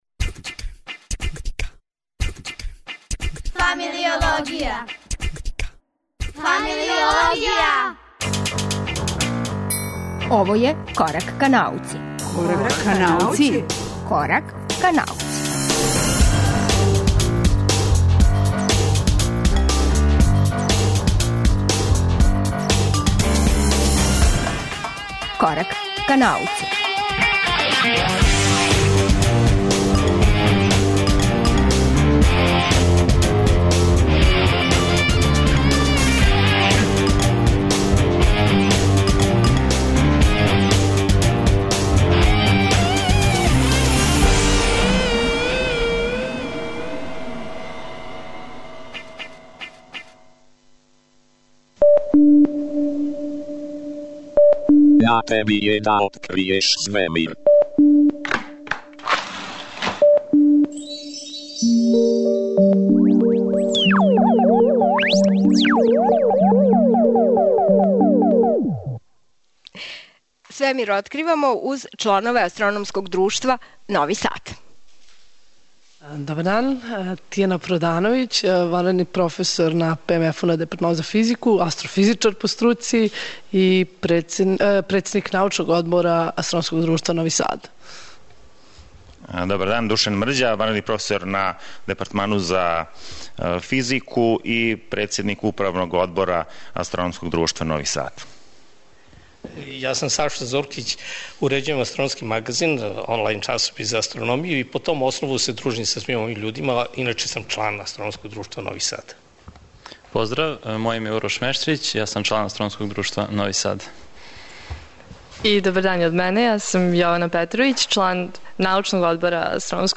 Емисија Корак ка науци емитује се из Новог Сада. Упознаћемо чланове АД Нови Сад, сазнати њихове планове за популаризацију науке у овој години, попричати о календару и хороскопима којих су пуне новине у ово доба године.